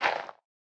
Minecraft Version Minecraft Version latest Latest Release | Latest Snapshot latest / assets / minecraft / sounds / block / composter / fill4.ogg Compare With Compare With Latest Release | Latest Snapshot